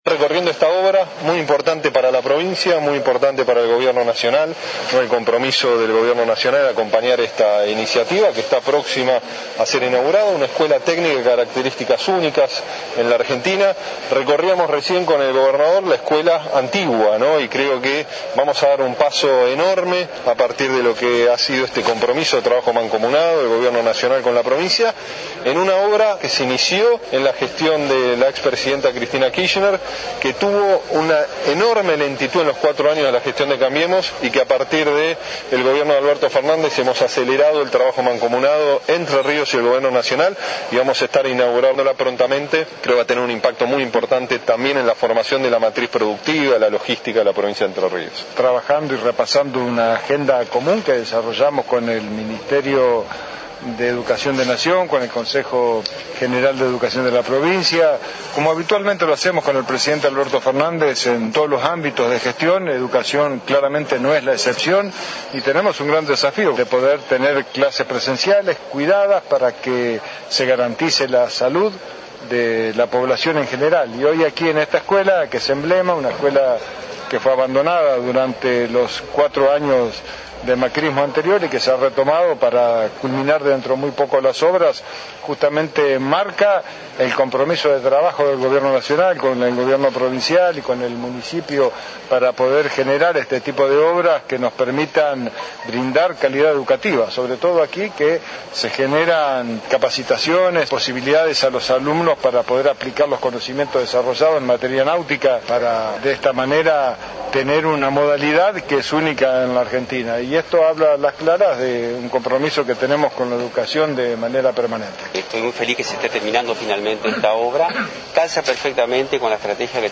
declaraciones Ministro de Educación NIcolás Trotta a medios provinciales